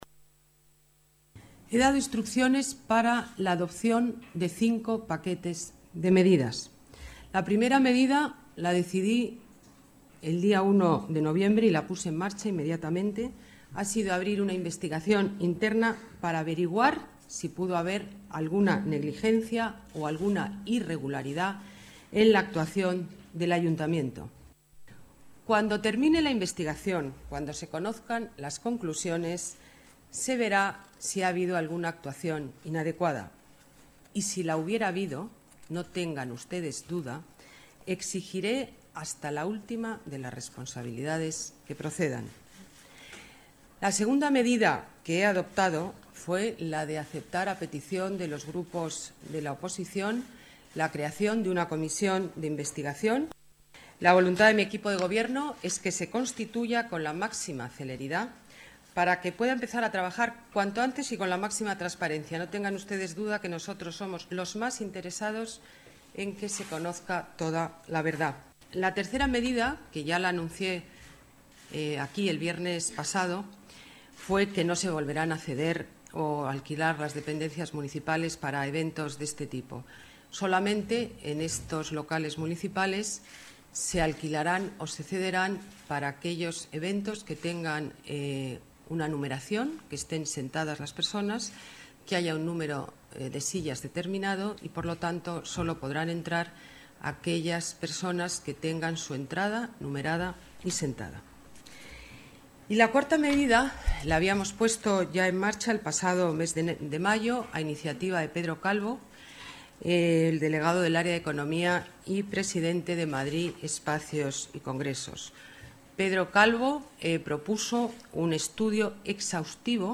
Durante la rueda de prensa, celebrada tras la Junta de Gobierno, la alcaldesa de la Ciudad de Madrid, Ana Botella, ha informado sobre las cinco medidas adoptadas por el Gobierno municipal en el caso